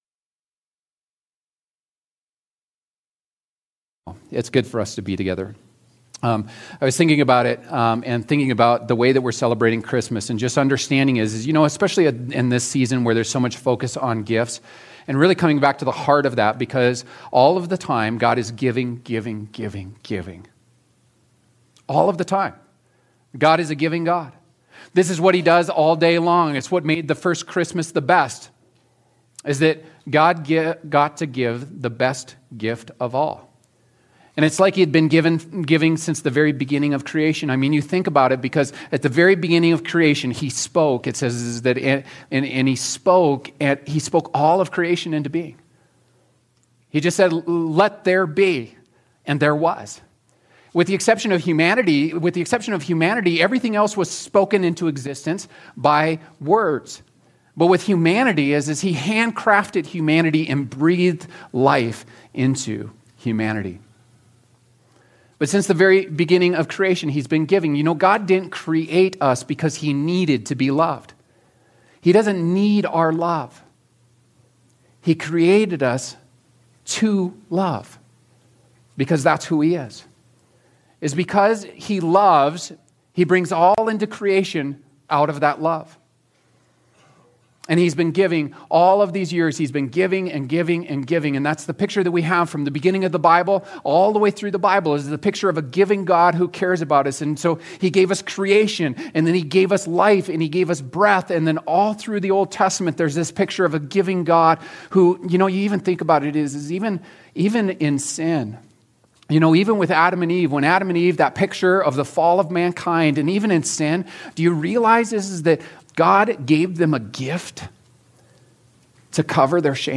A message from the series "The Gospel Of Matthew."